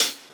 Boom-Bap Hat CL 72.wav